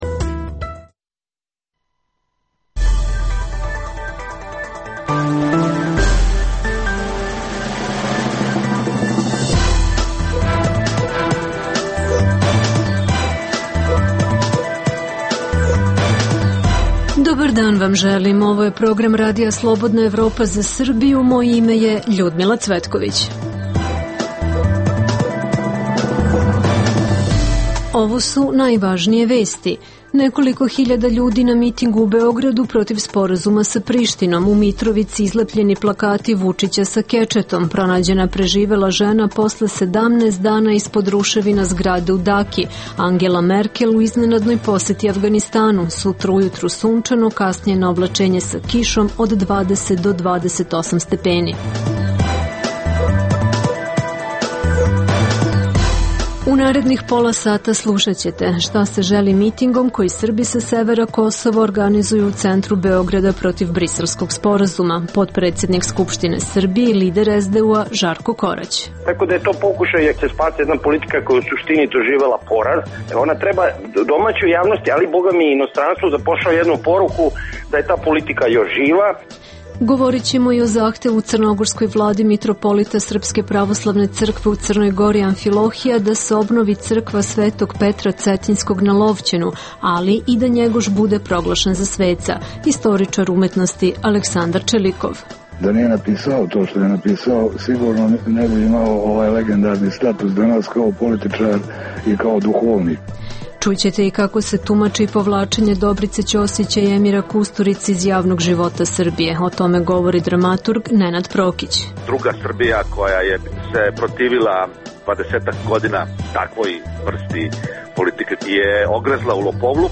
- Srbi sa severa Kosova i pojedine vladike SPC na mitingu u Beogradu optužuju vlast za izdaju Kosova. Čućete učesnike mitinga i kakvo je interesovanje Beograđana.